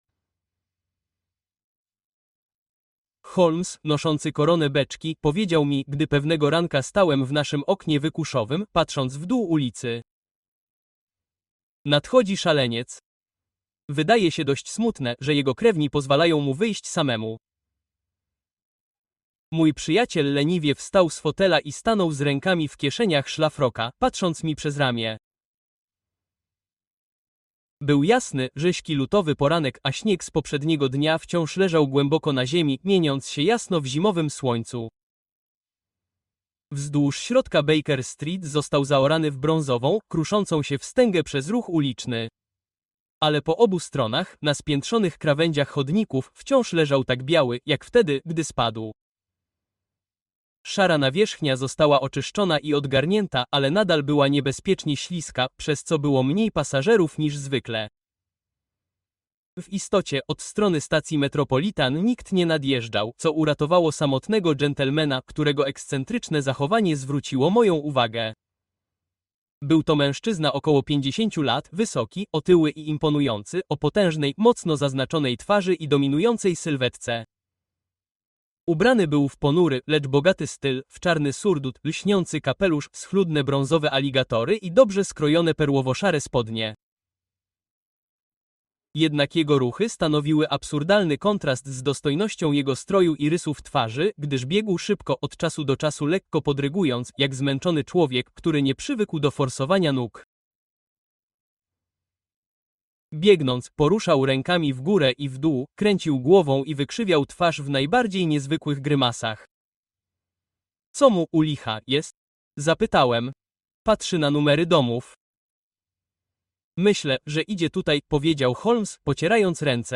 The Mazarin Stone: Sherlock Holmes’ Most Intriguing Case (Audiobook)